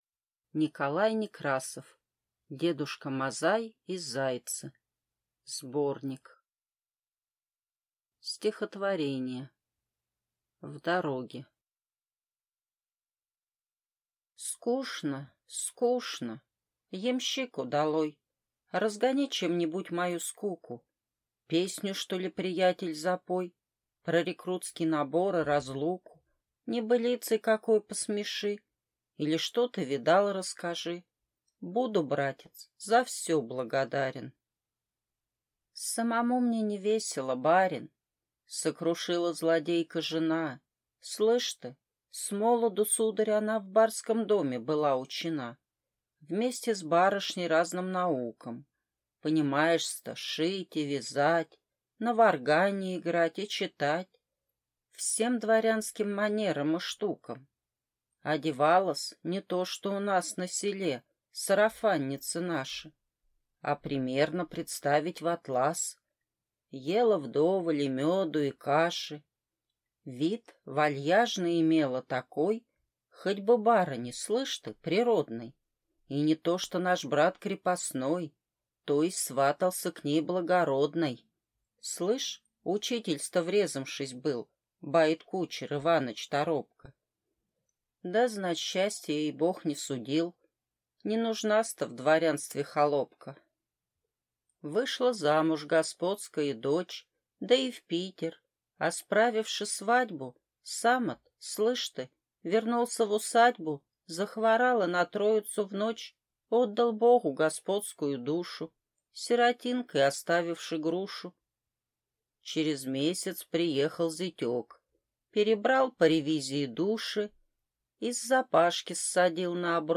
Аудиокнига Дедушка Мазай и зайцы (сборник) | Библиотека аудиокниг